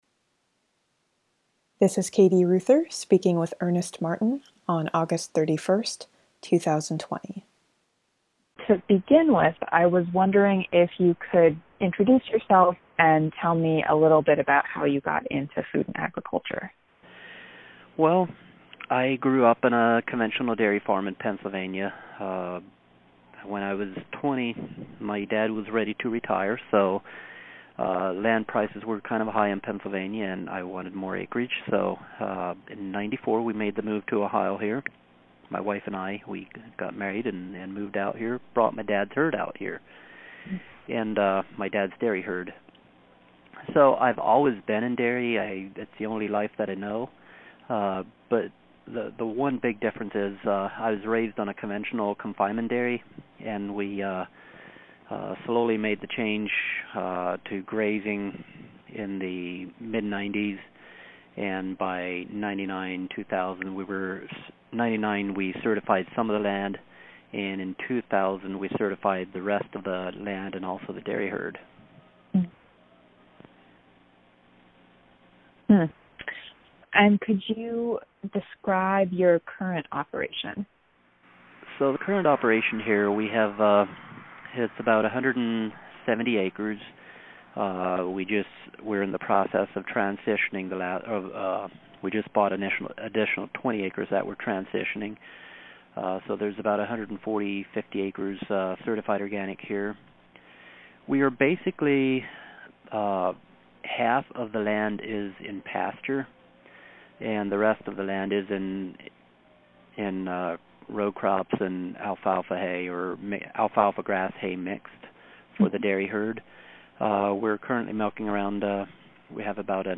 Interview
Remote interview